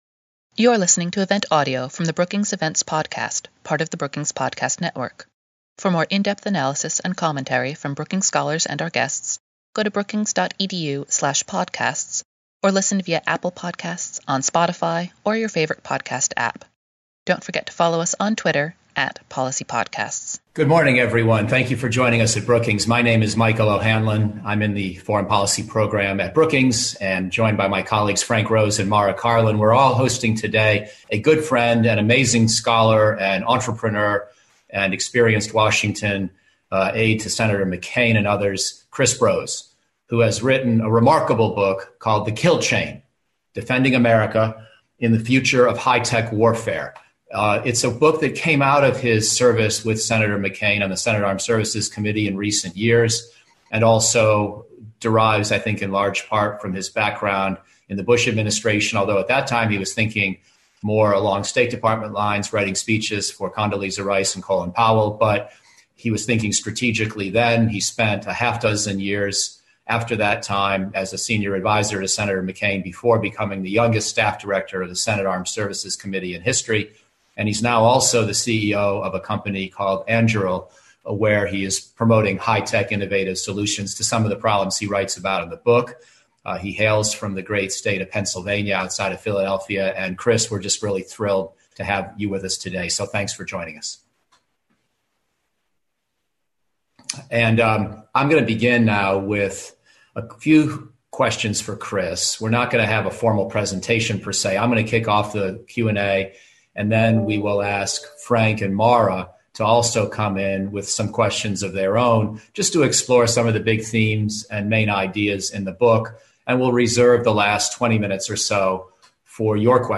Webinar: The future of high-tech warfare | Brookings